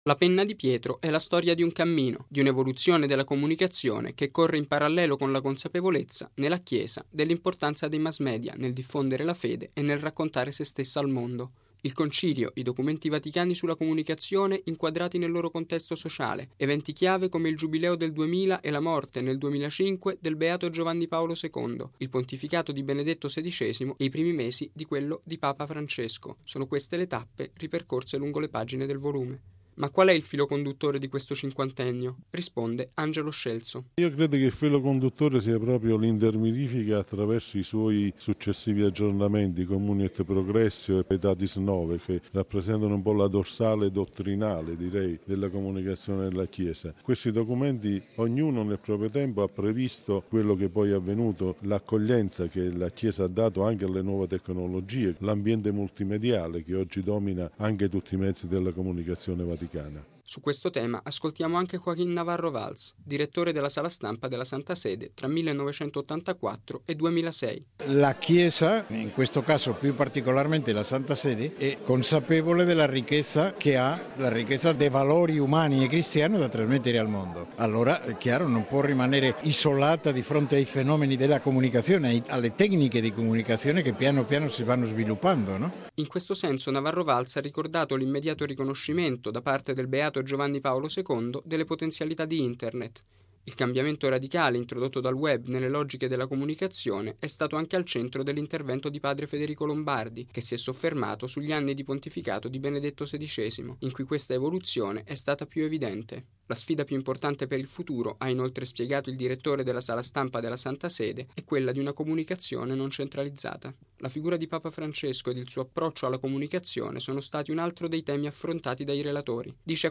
Il volume è stato presentato ieri a Roma nell’Aula Magna dell’Università Lumsa, con l’intervento, tra gli altri, di padre Federico Lombardi, direttore della Sala Stampa e direttore generale della Radio Vaticana.